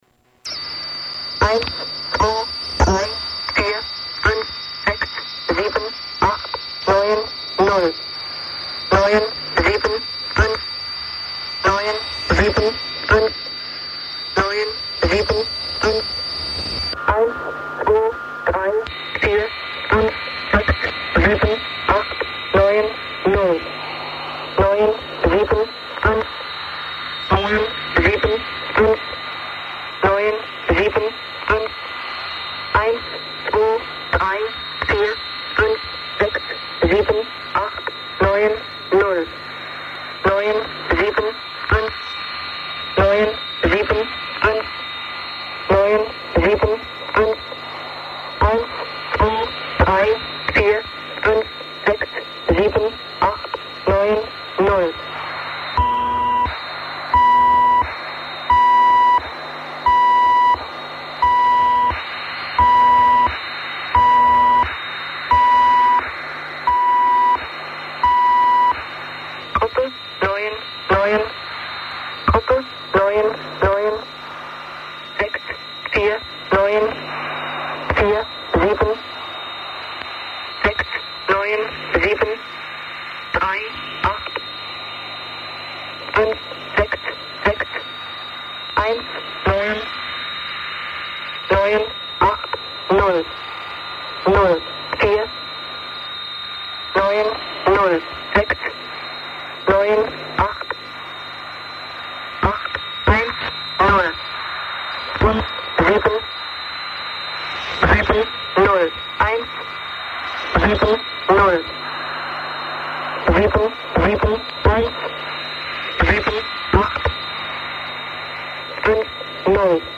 Tags: Radio Broadcast Secret Spy Broadcast NATO Number Station